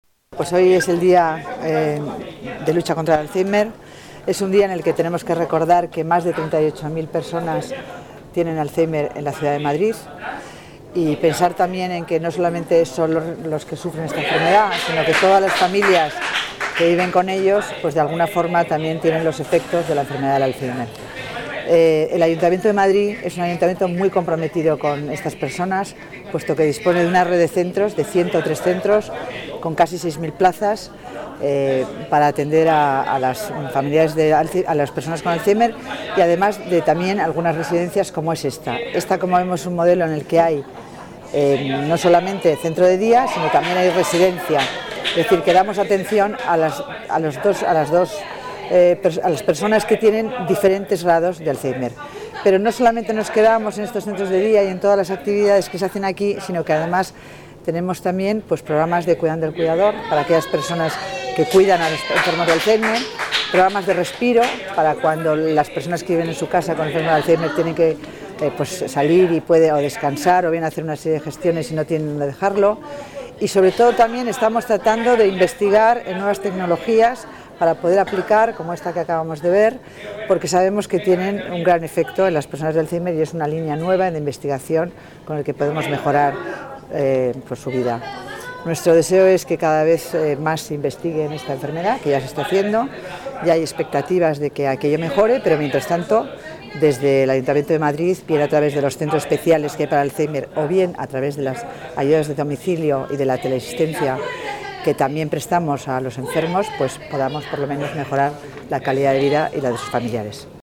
Nueva ventana:Declaraciones de Concepción Dancausa: Día Alzheimer